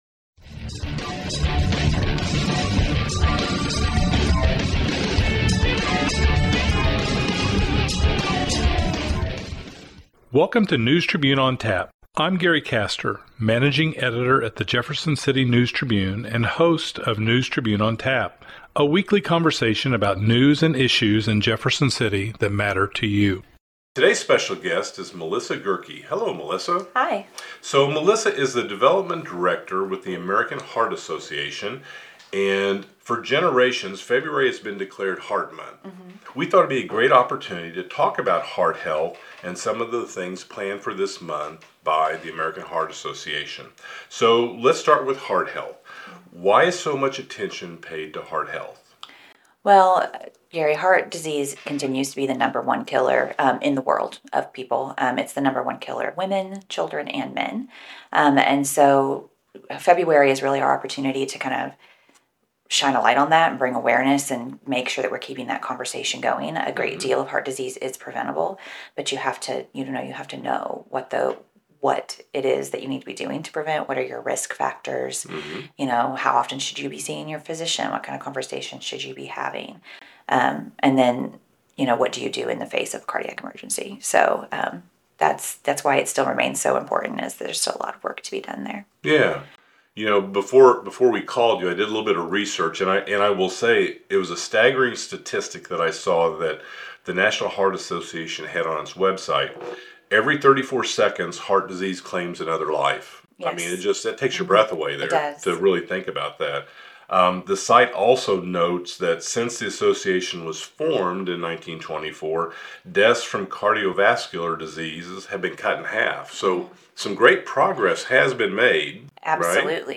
The interview further explores differences in heart attack symptoms between men and women and advocates for prompt action when symptoms arise.